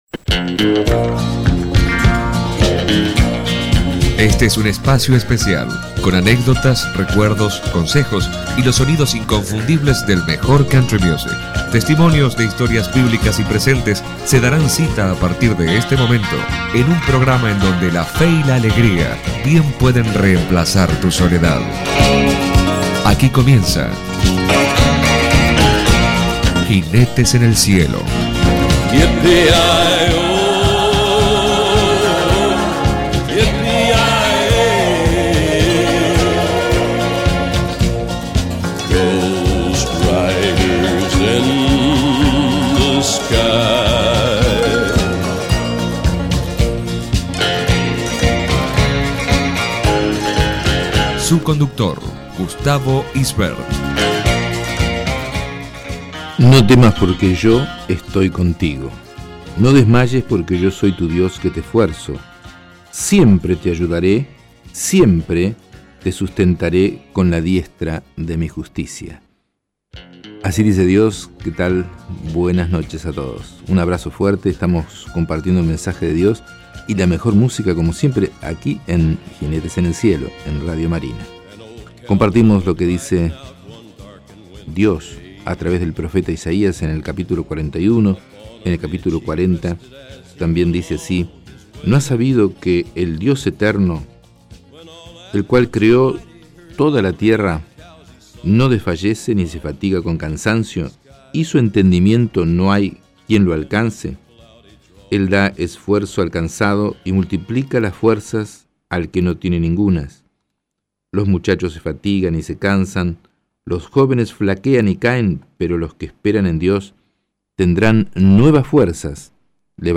Programa de Radio Jinetes en el Cielo para escuchar o regalar donde una sólida palabra de Dios nos anima a vencer la incertidumbre y los temosres y nos enseña cómo poder superar cada prueba partiendo de la fe en nuestro Señor Jesucristo. Jinetes en el Cielo programa de radio Cristiano.